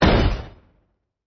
WAV · 55 KB · 單聲道 (1ch)